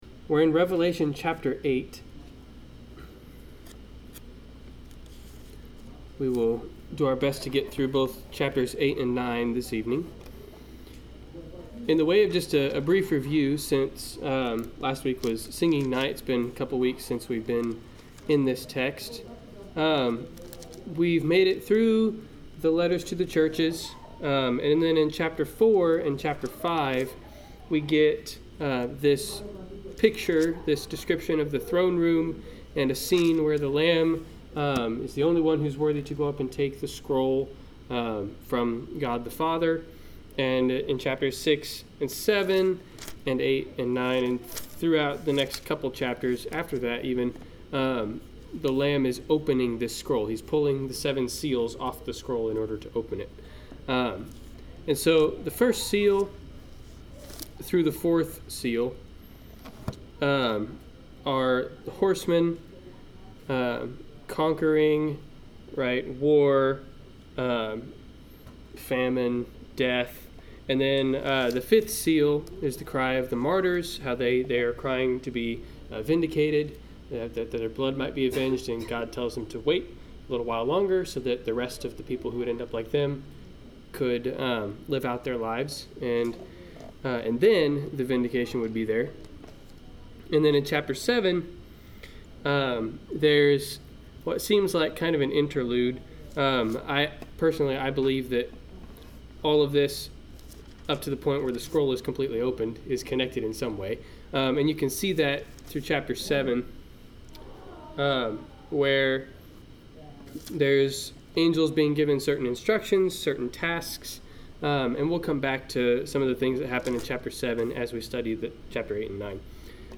Passage: Revelation 8-9 Service Type: Wednesday Night Class